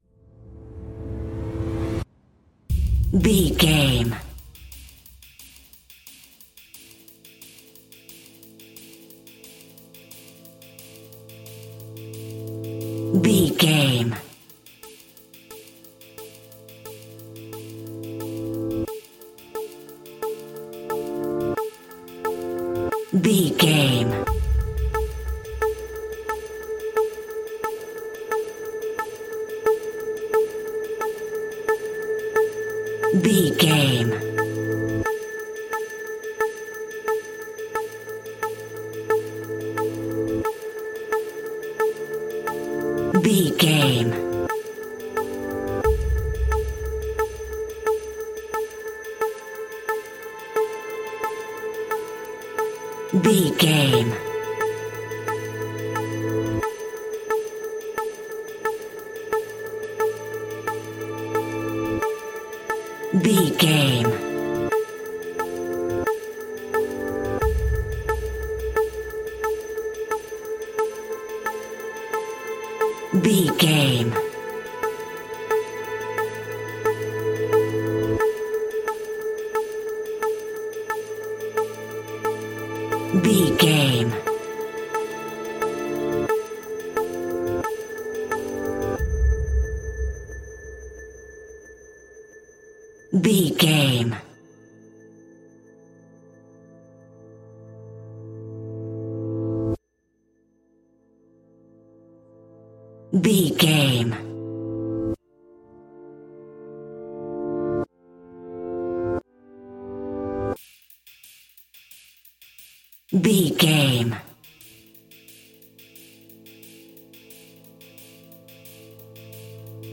Ionian/Major
A♭
electronic
techno
trance
synths
synthwave
instrumentals